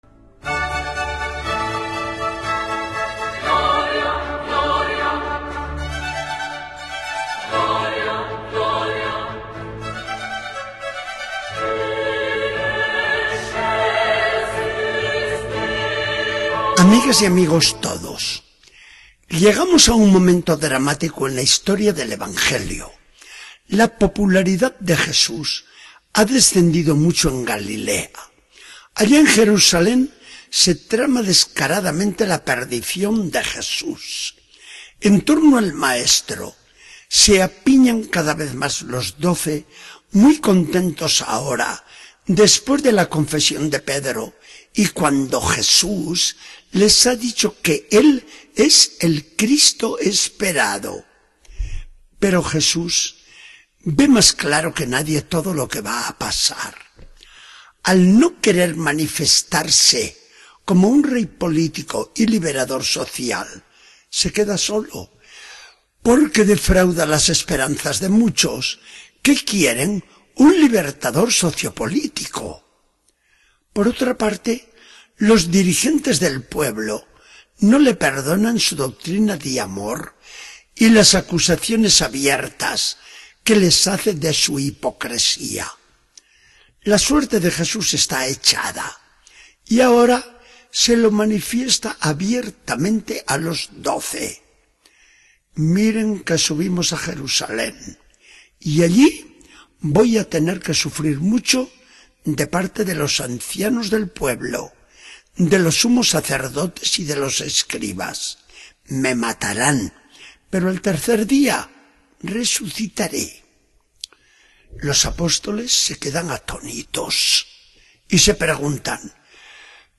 Charla del día 31 de agosto de 2014. Del Evangelio según San Mateo 16, 21-27.